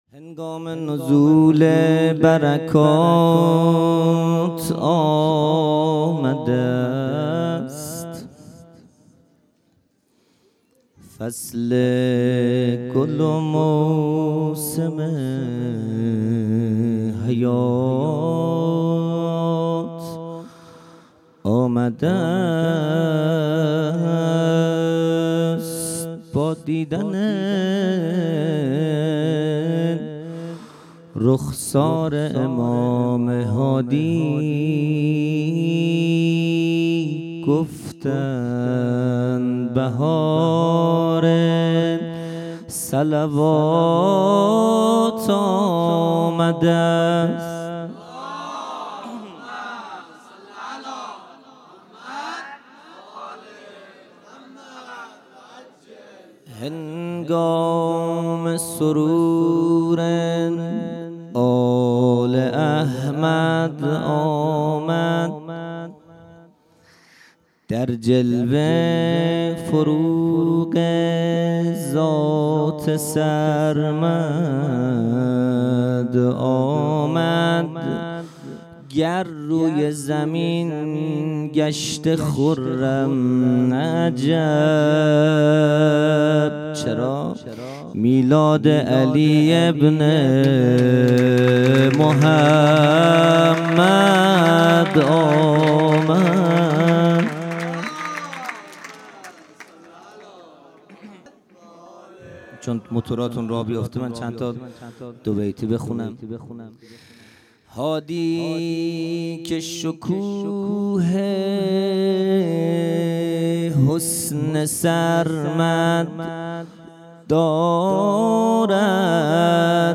خیمه گاه - هیئت بچه های فاطمه (س) - مدح | هنگام نزول برکات آمده است
جلسۀ هفتگی (به مناسبت ولادت امام هادی(ع))